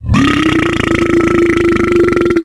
boomer_dive_01.wav